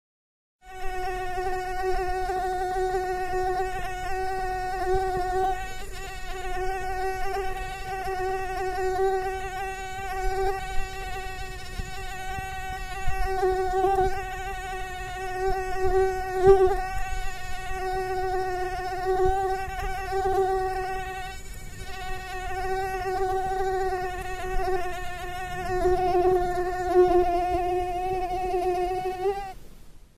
Categoria Efeitos Sonoros